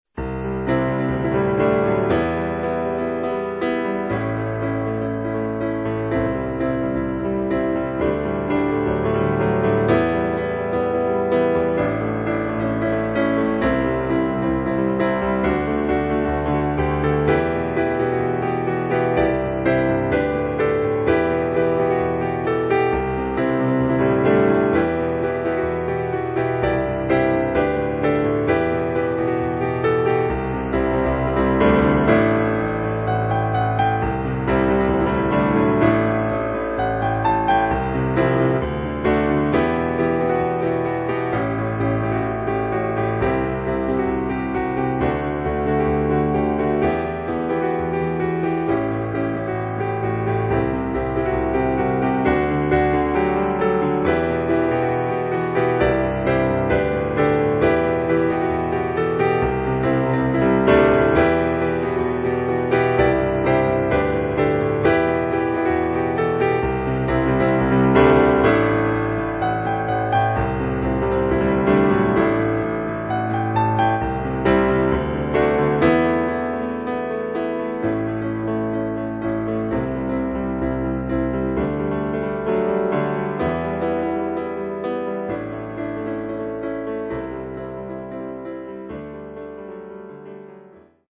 Now, I just wish I could play it on a real piano.